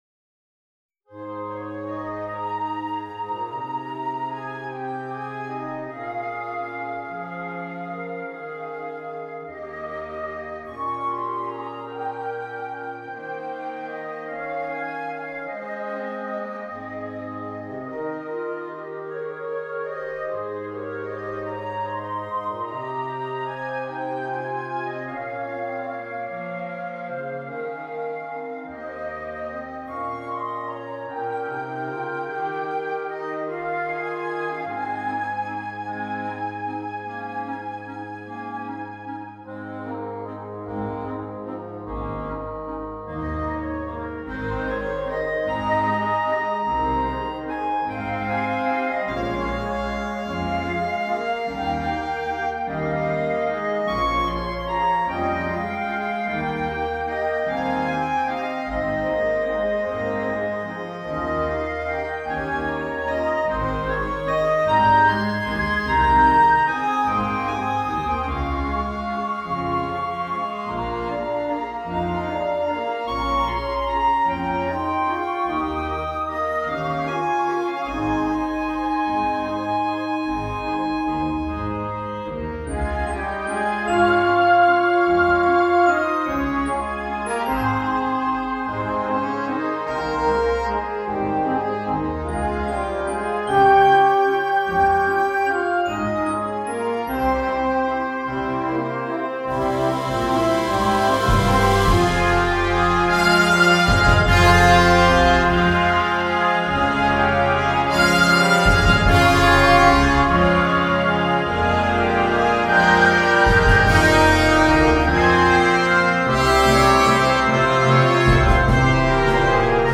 Wind Ensemble – Grade 4